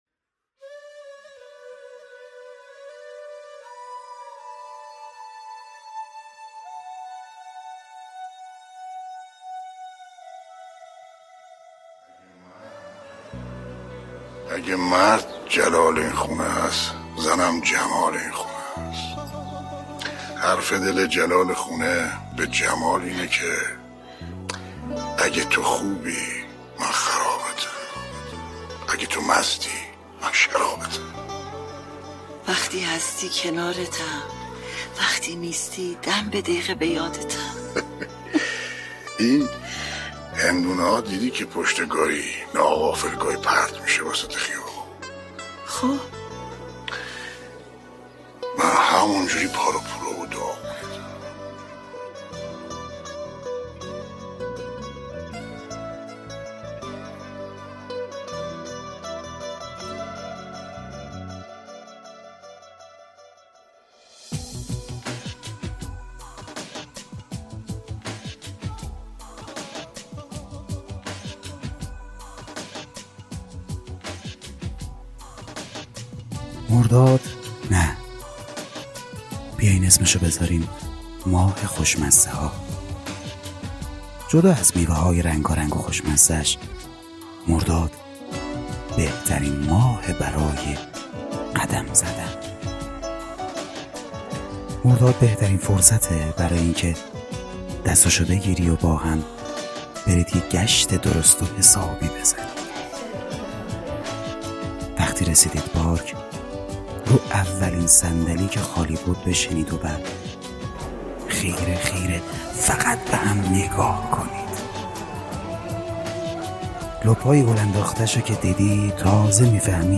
بک وکال